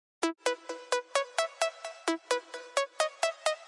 130 BPM 拨号
描述：这是使用Spire创建的拔除声音，并使用第三方插件和效果进行处理。
标签： 循环 音乐 130 样品 EDM 拔毛 BPM 恍惚间 舞蹈
声道立体声